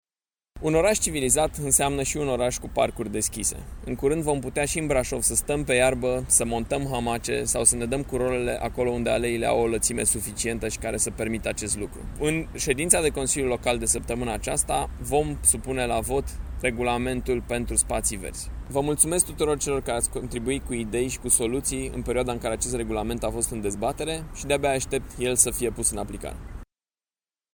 Primarul Brașovului, Allen Coliban: